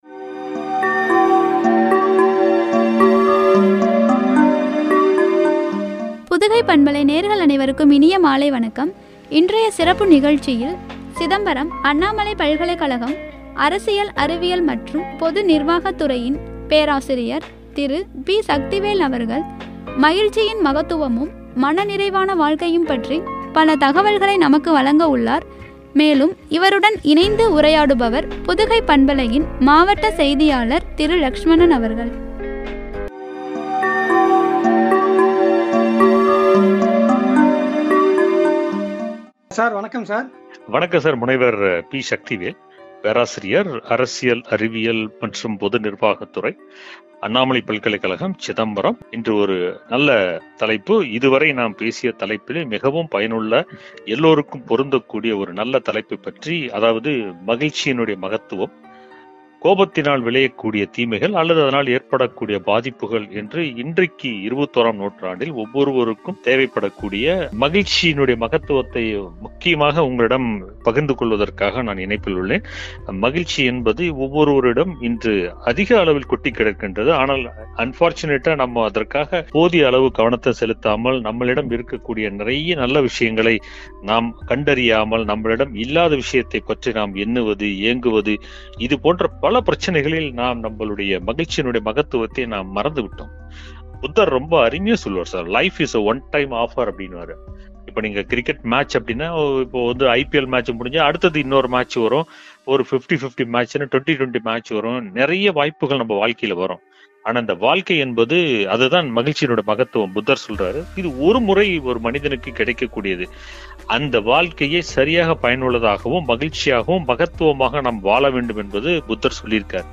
மனநிறைவான வாழ்க்கையும்” குறித்து வழங்கிய உரையாடல்.